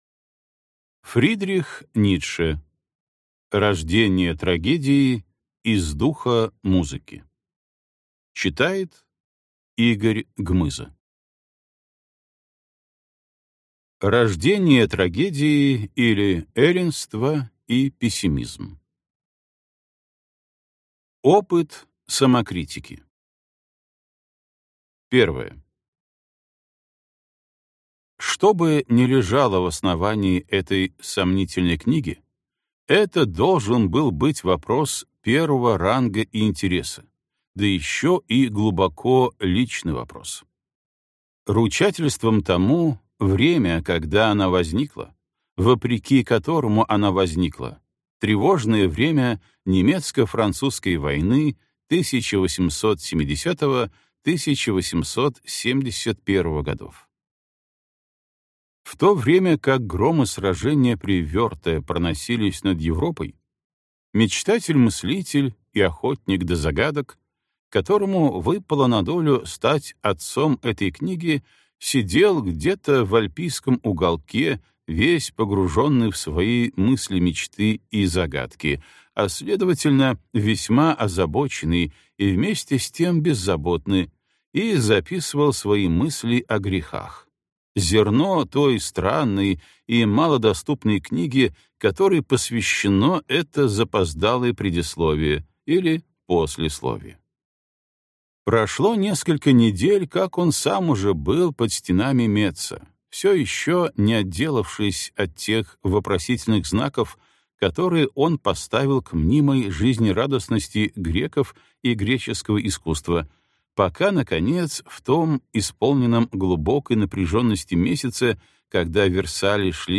Аудиокнига Рождение трагедии из духа музыки (сборник) | Библиотека аудиокниг